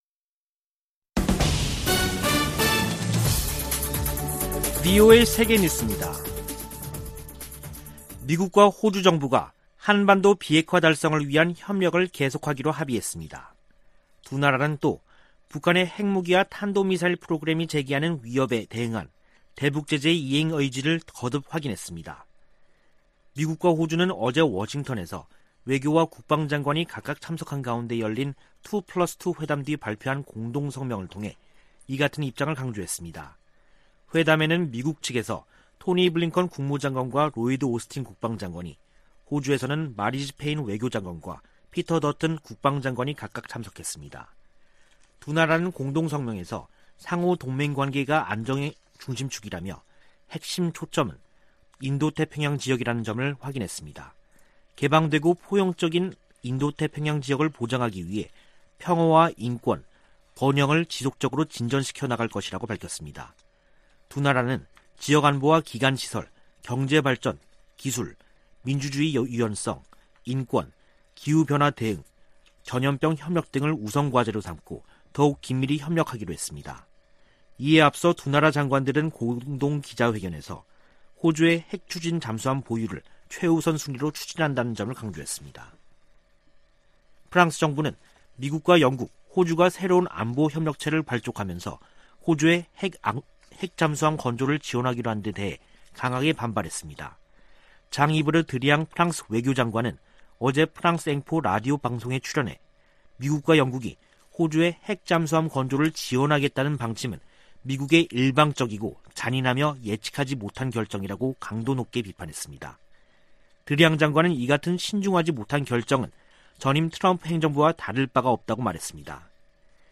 VOA 한국어 간판 뉴스 프로그램 '뉴스 투데이', 2021년 9월 17일 2부 방송입니다. 북한이 영변 우라늄 농축 시설을 확장하는 정황이 담긴 위성사진이 공개됐습니다. 76차 유엔총회가 14일 개막된 가운데 조 바이든 미국 대통령 등 주요 정상들이 어떤 대북 메시지를 내놓을지 주목됩니다. 유럽연합(EU)은 올해도 북한 인권 규탄 결의안을 유엔총회 제3위원회에 제출할 것으로 알려졌습니다.